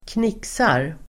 Uttal: [²kn'ik:sar]